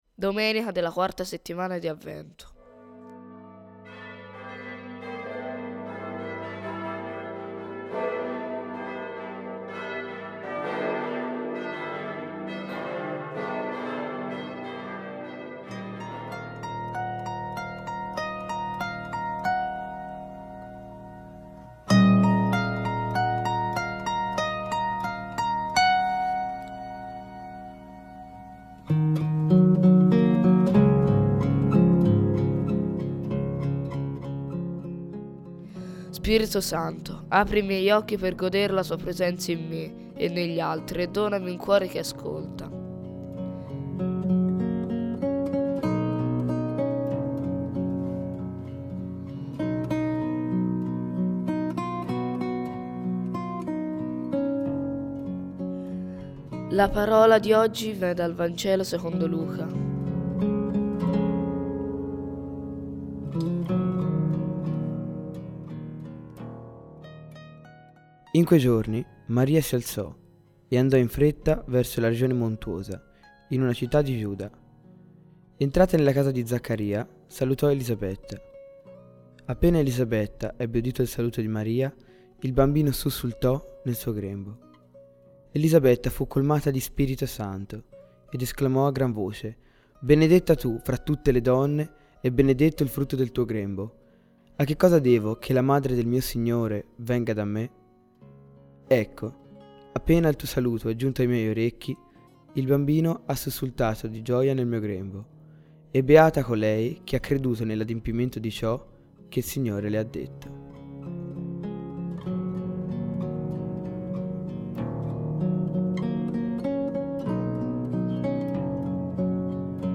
Voci narranti
18 String Harp Guitar Cover